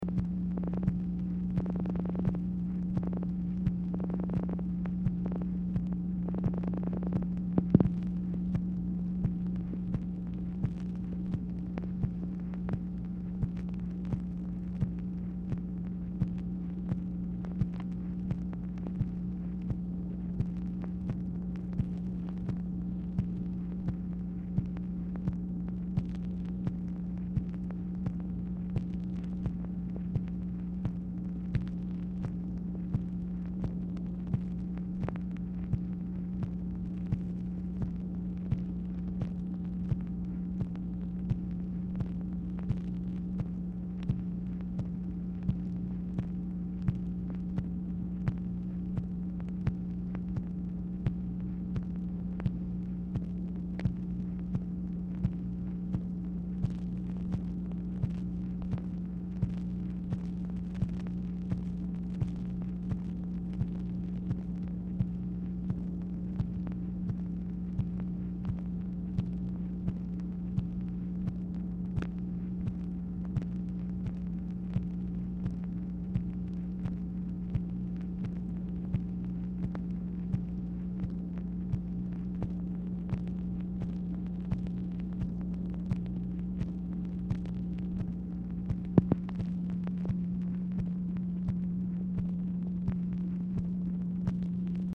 MACHINE NOISE
Oval Office or unknown location
Telephone conversation
Dictation belt